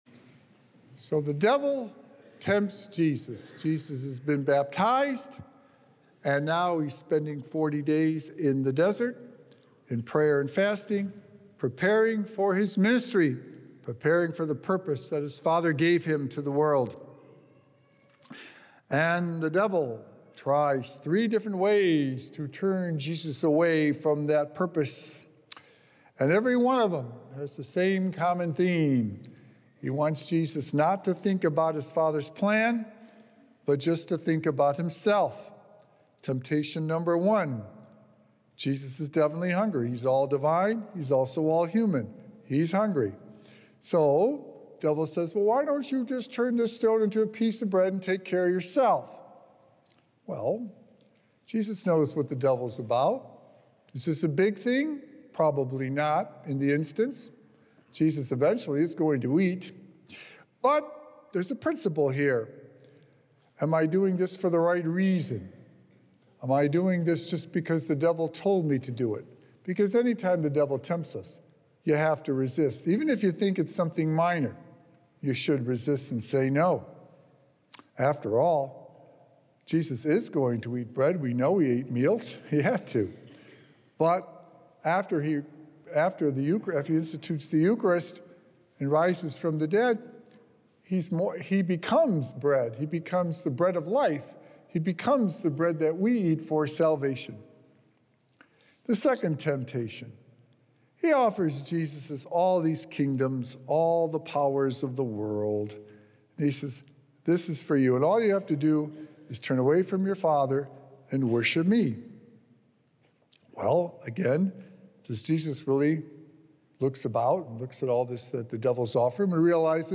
Today's Gospel reading about the temptation of Satan to Jesus offers us a deeper understanding of how important it is to stand tall in our faith and to resist what the devil asks of us. Recorded Live on Sunday, March 9th, 2025 at St. Malachy Catholic Church.